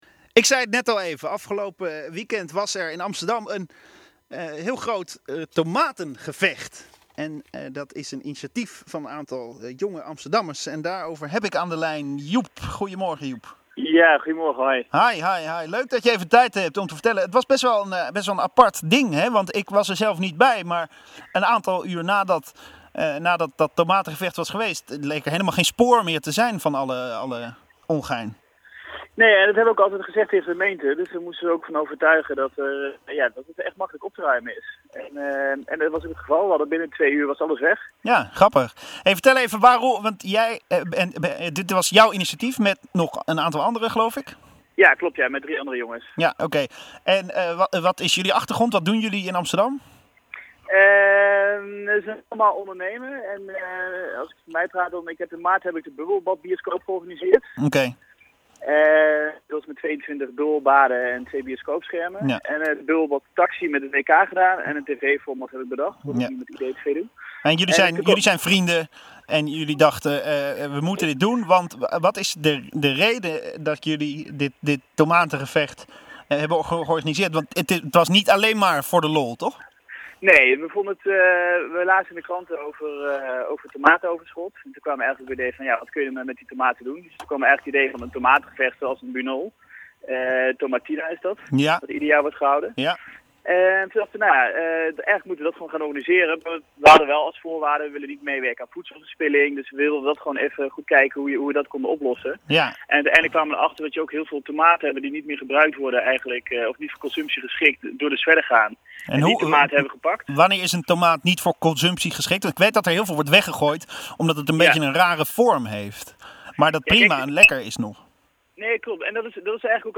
Gesprek-tomatengevecht.mp3